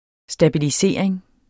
Udtale [ sdabiliˈseˀɐ̯eŋ ]